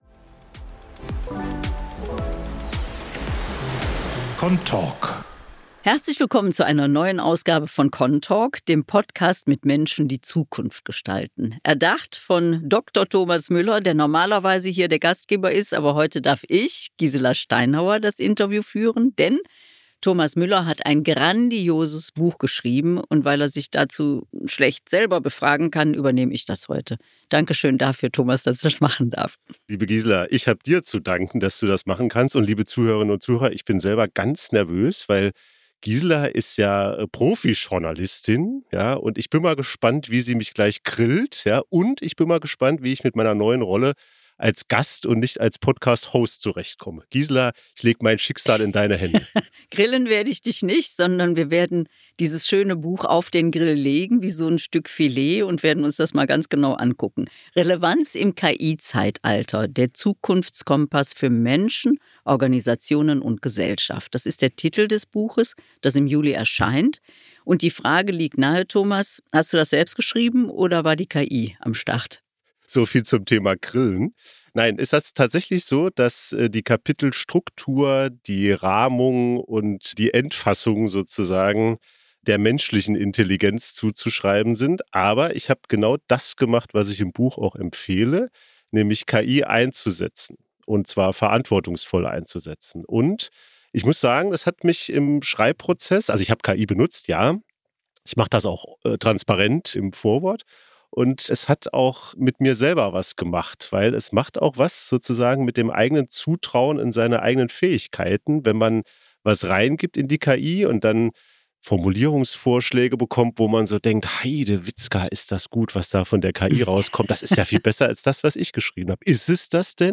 Journalistin, Autorin und Moderatorin